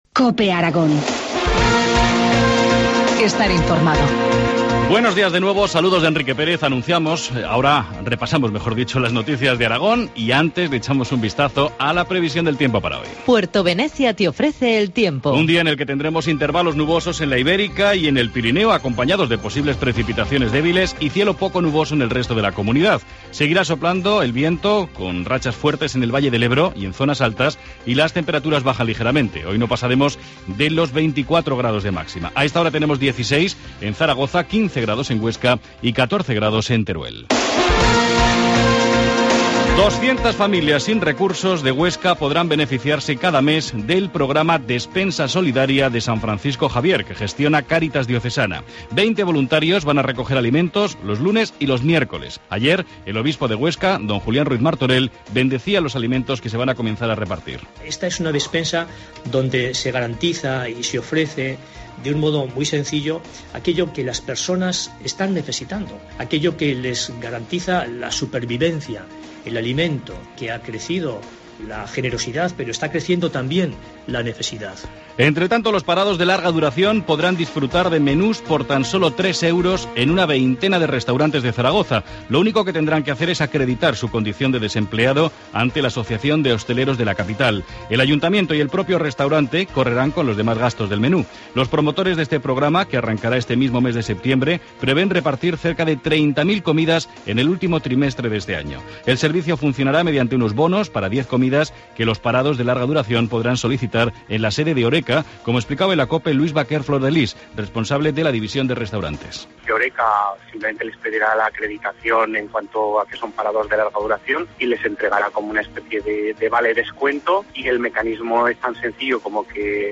Informativo matinal, martes 17 de septiembre, 8.25 horas